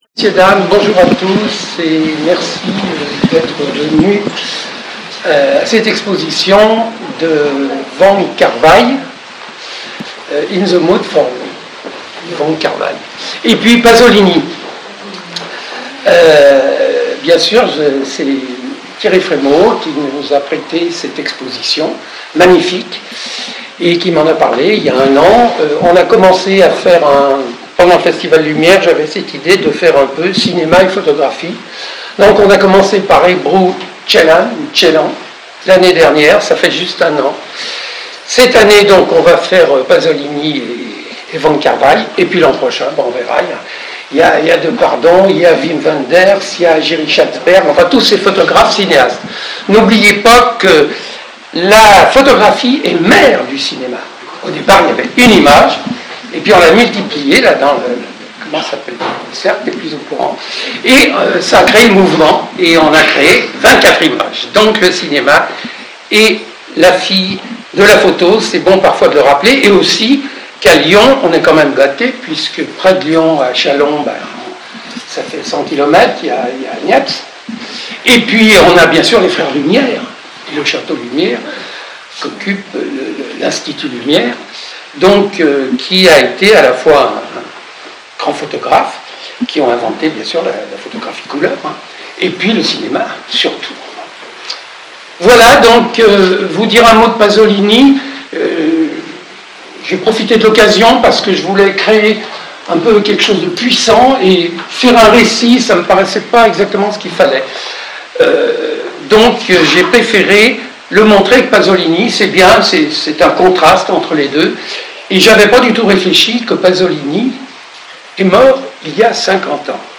inauguration de l'exposition In the mood for Wong Kar Wai